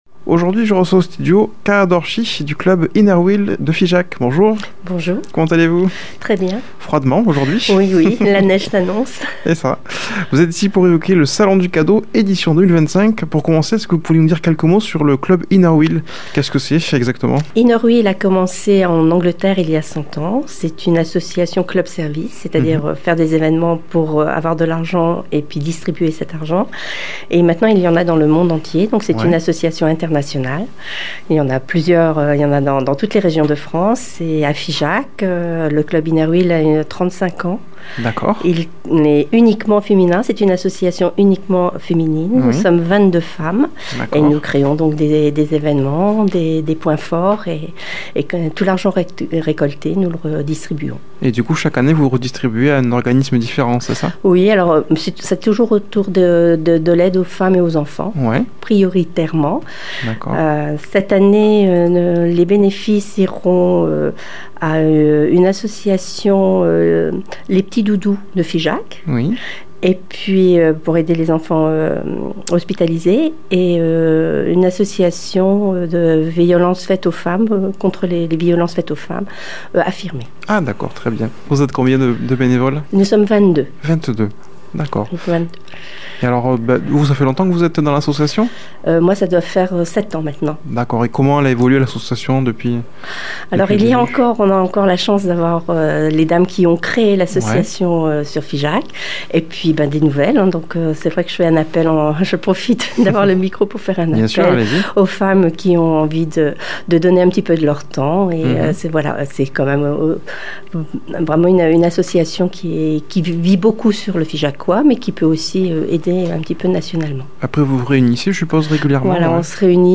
a comme invitée au studio